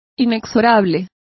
Complete with pronunciation of the translation of inflexible.